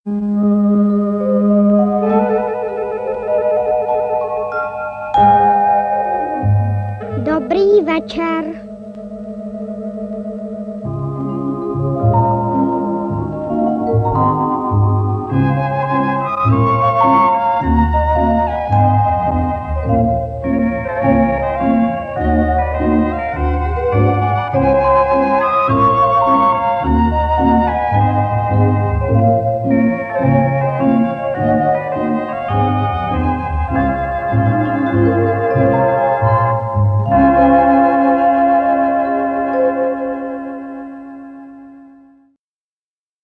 Znělky a písničky